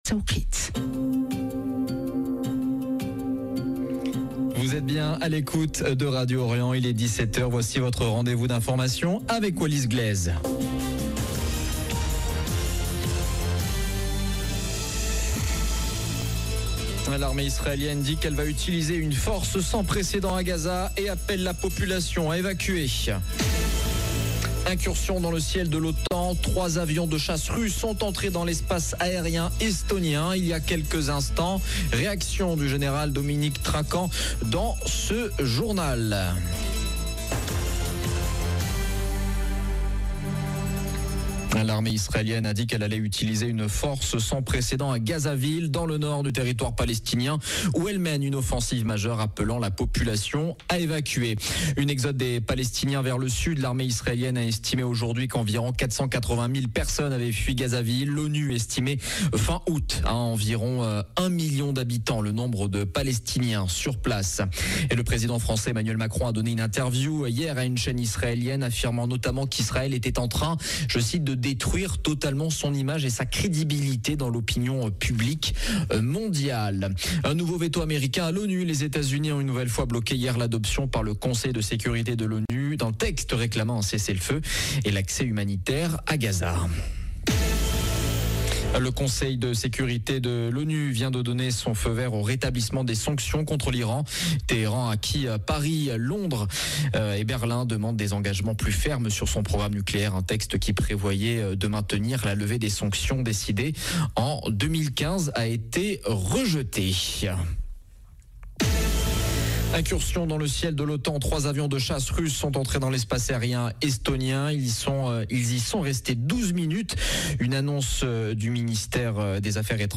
Journal de 17H du 19 septembre 2025